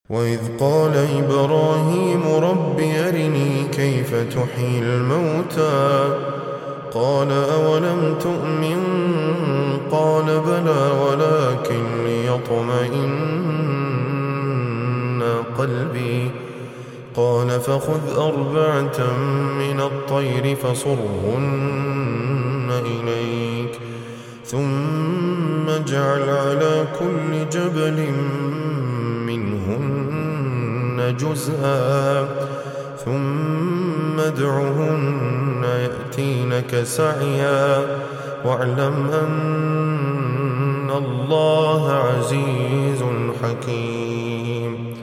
A peaceful recitation of alquran